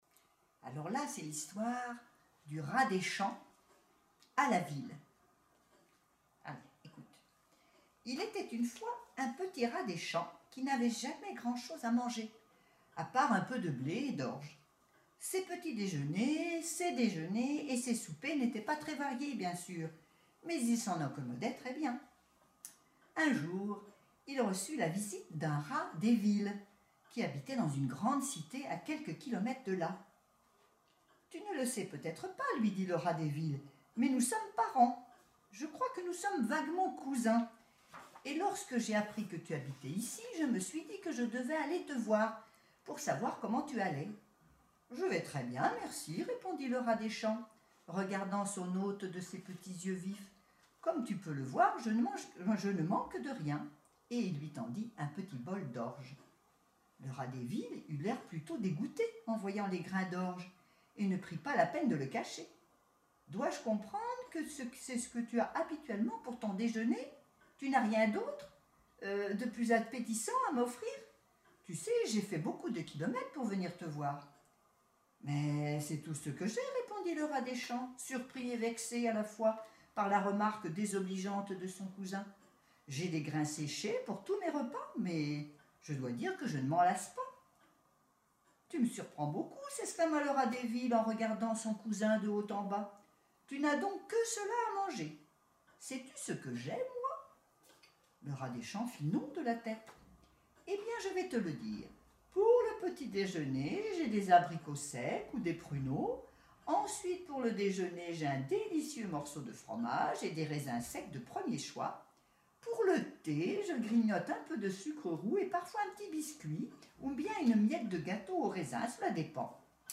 Une histoire racontée